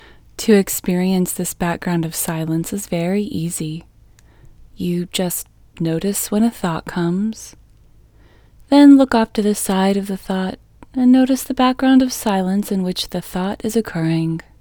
LOCATE Short IN English Female 7